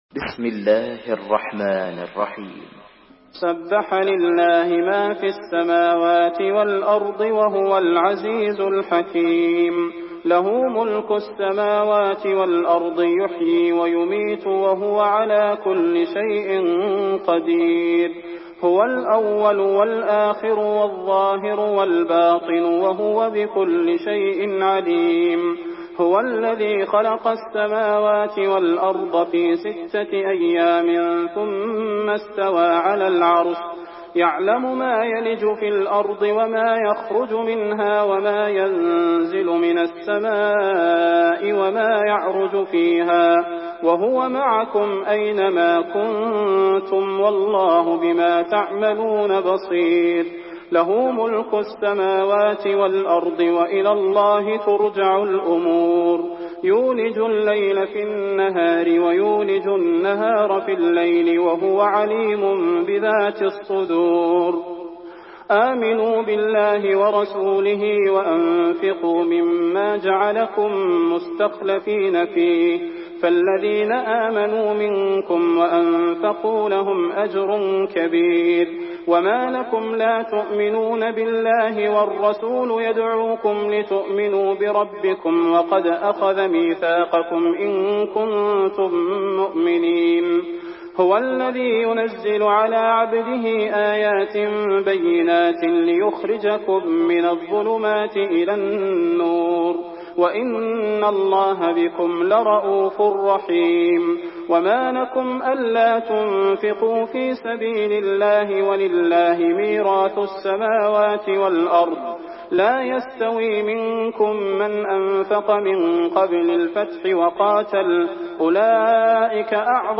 Surah الحديد MP3 by صلاح البدير in حفص عن عاصم narration.
مرتل حفص عن عاصم